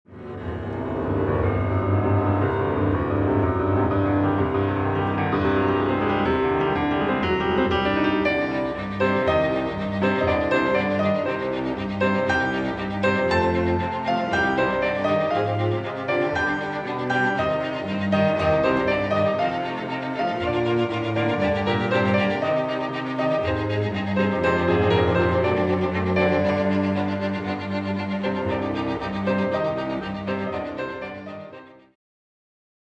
Piano Quintet in C Minor